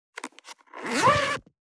427,ジッパー,チャックの音,洋服関係音,ジー,バリバリ,
ジッパー